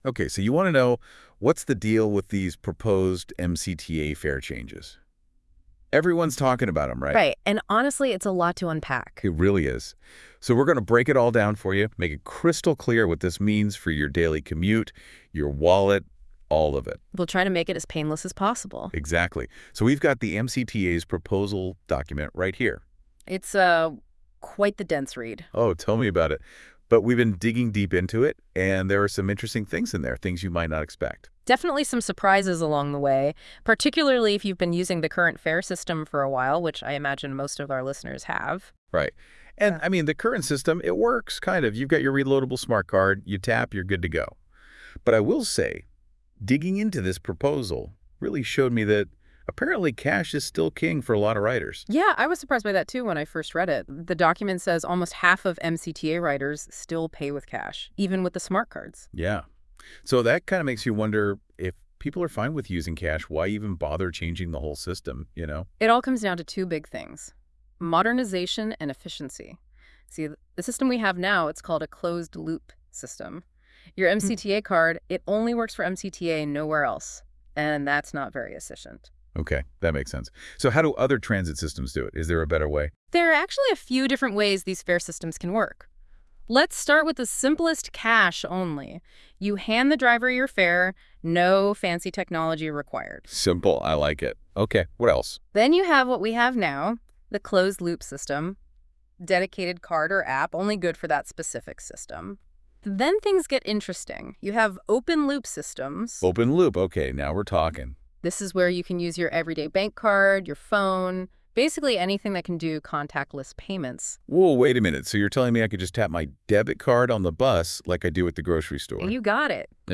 This podcast was created with NotepadLM, a Google AI product. The AI system generated the podcast based on the document linked at the bottom of the page.